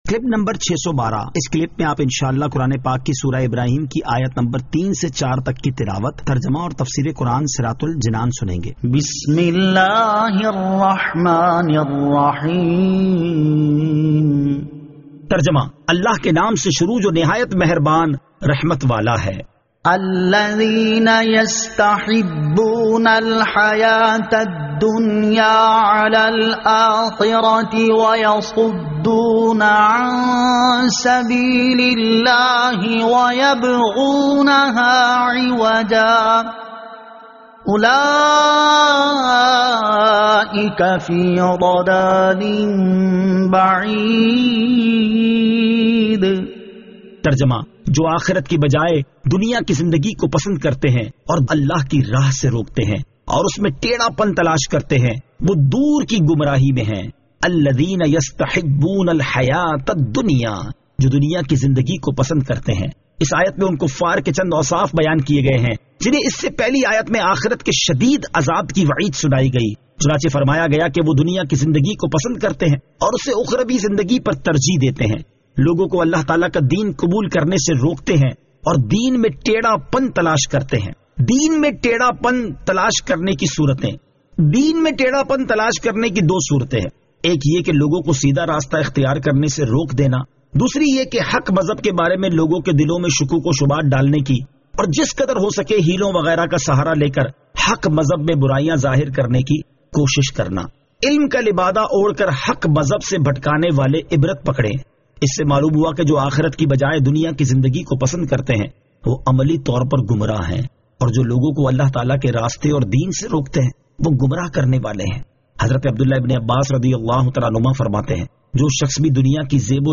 Surah Ibrahim Ayat 03 To 04 Tilawat , Tarjama , Tafseer